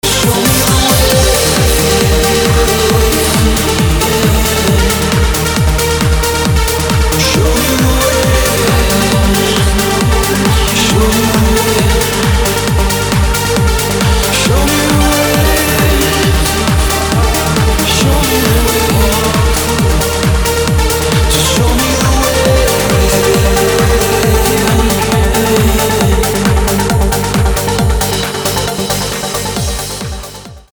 мужской вокал
электронная музыка
Trance